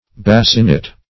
Bascinet \Bas"ci*net\, n.